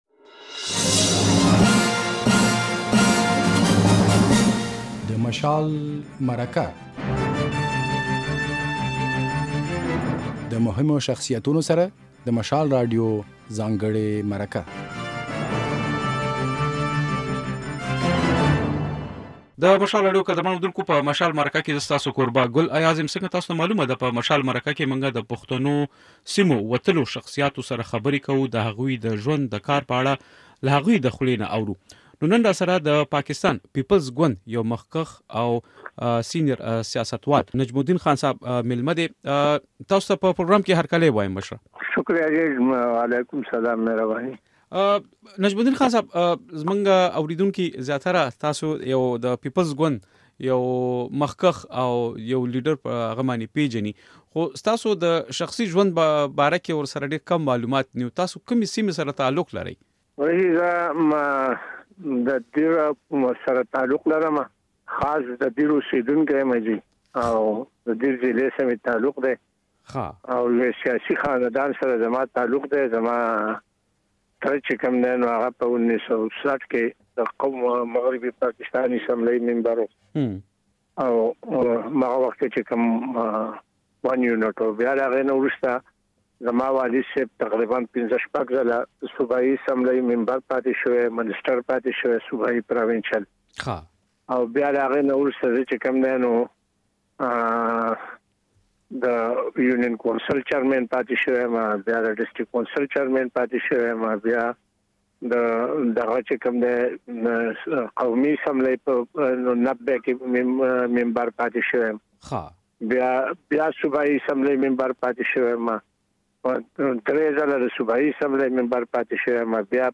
په مشال مرکه کې د پيپلز ګوند د خيبر پښتونخوا صوبايي مشر نجم الدين خان ميلمه دی.